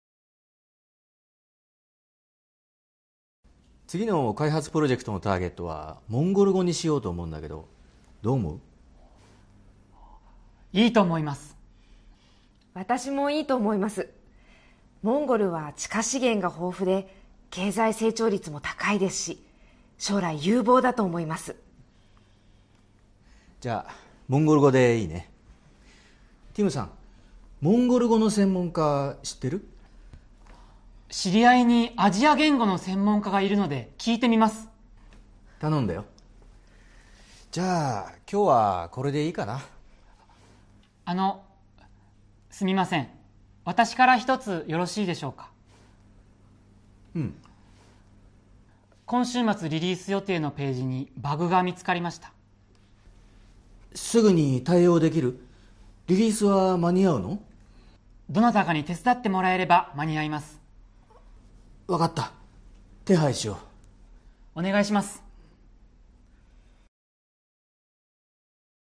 Role-play Setup
Conversation Transcript
skit22.mp3